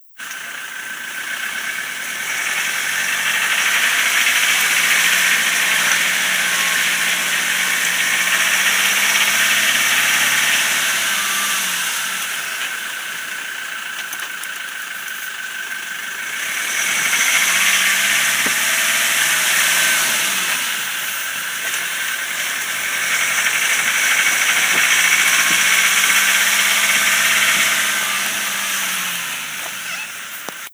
2.4 "clacking"!
It still runs well but generally sounds a little noisier than normal even when it's not "clacking".
No, definitely in the engine somewhere, it only happens under load and at certain revs.
Have a listen to the wav, it's fairly obvious if you listen a couple of times and filter out the normal Defender din!
Land Rover Clack.wav